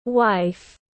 Vợ tiếng anh gọi là wife, phiên âm tiếng anh đọc là /waɪf/.
Wife /waɪf/